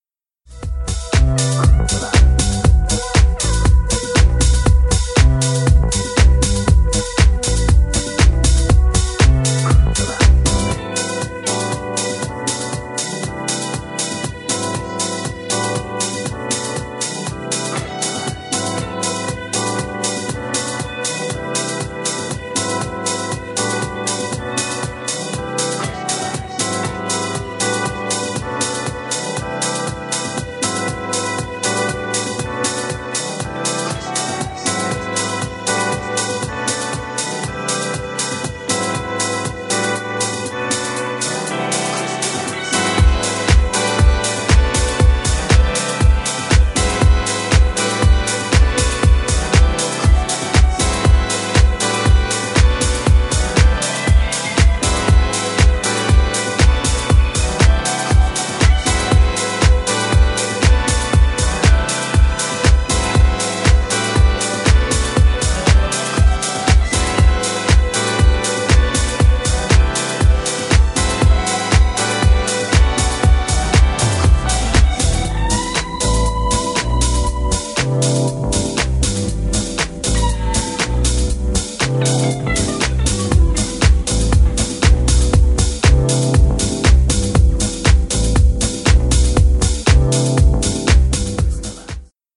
ソウルフル＆ディープなハウスで超超超おすすめの1枚！！
ジャンル(スタイル) HOUSE / SOULFUL HOUSE